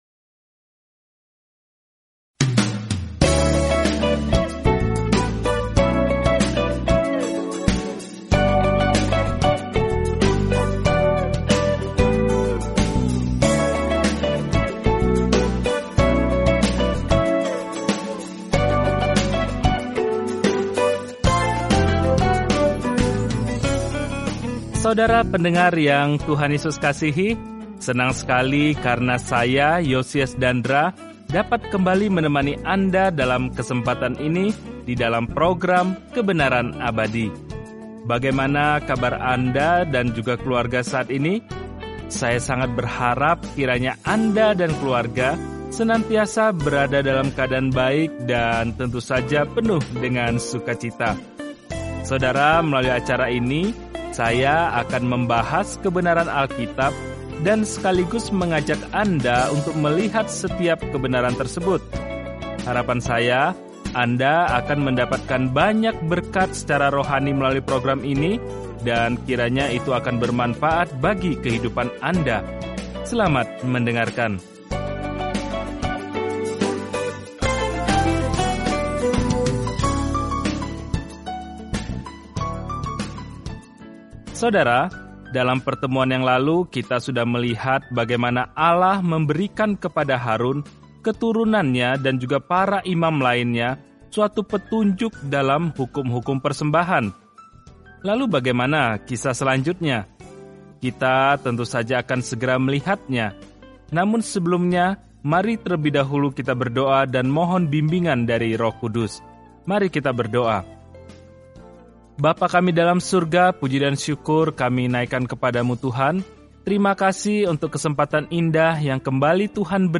Dalam ibadah, pengorbanan, dan rasa hormat, Imamat menjawab pertanyaan itu bagi Israel zaman dahulu. Jelajahi Imamat setiap hari sambil mendengarkan studi audio dan membaca ayat-ayat tertentu dari firman Tuhan.